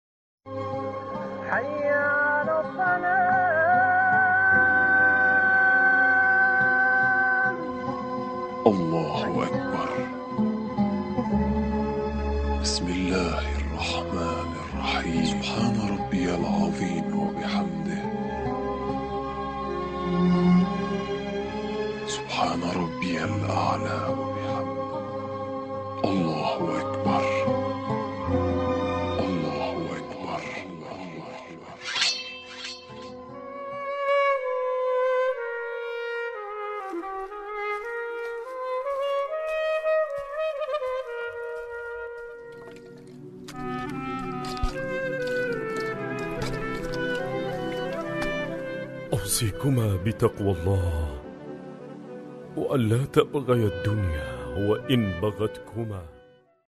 ملف صوتی اللهم العن قتلة أمير المؤمنين بصوت الشيخ الدكتور أحمد الوائلي